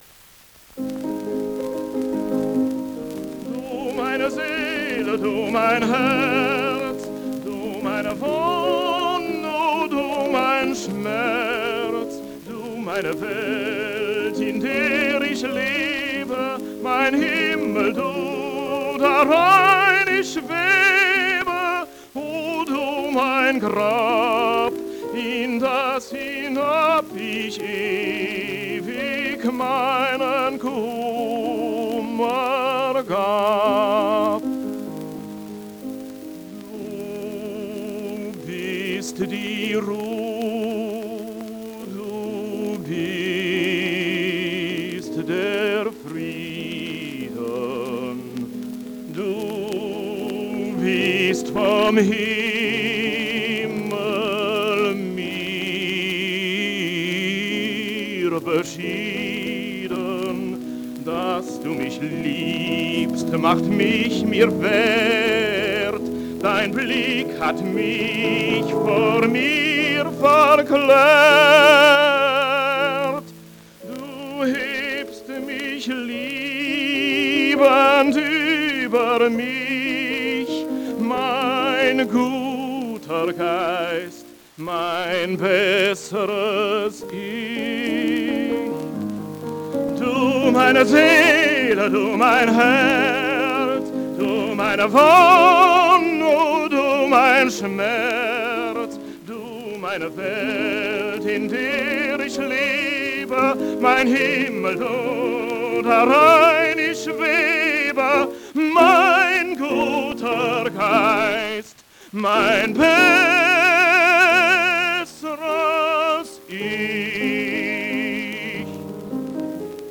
He studied voice (and acting with Max Reinhardt!), and became a professional tenor.
The Staatsoper Berlin connection sounds highly plausibel: his recordings are accompanied by that theater's orchestra.